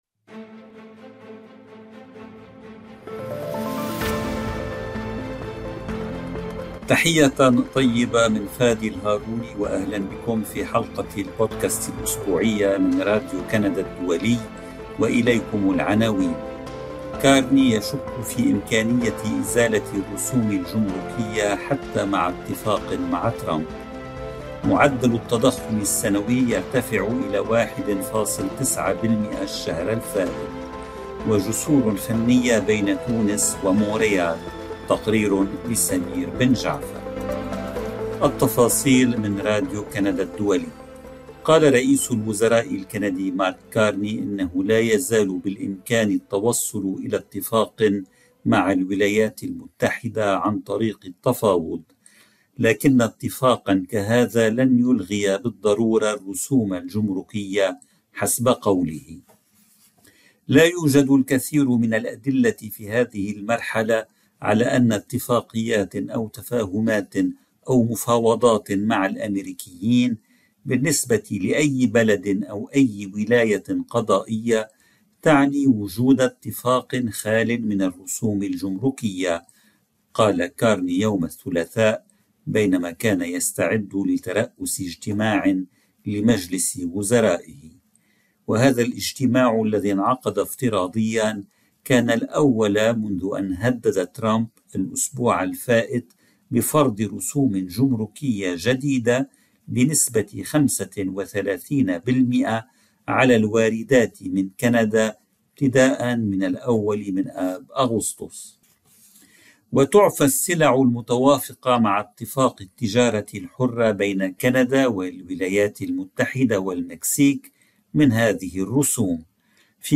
أخبار كندا في 10 دقائق – الحلقة 215 : 18 يوليو 2025 – العربية | RCI